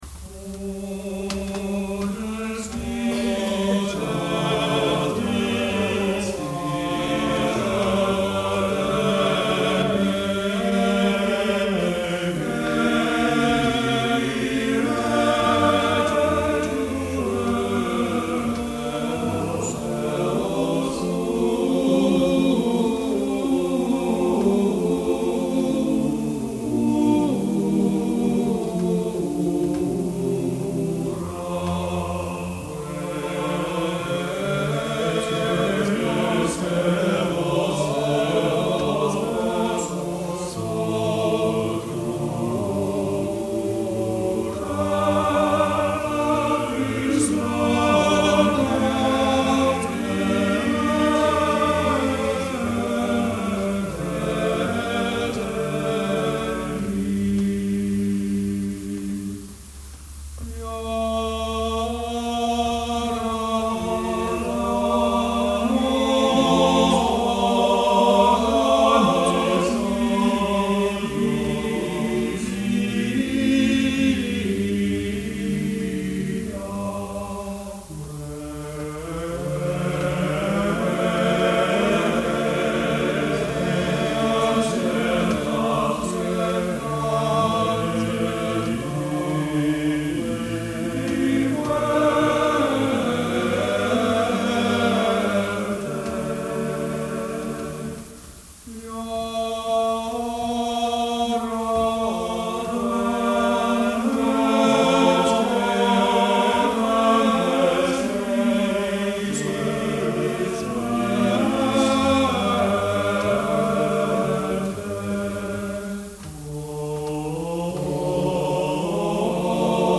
This soulful villancico for men’s voices a3 is #49 of the 468 pieces in the Cancionero de Palacio, thus likely to be an early work.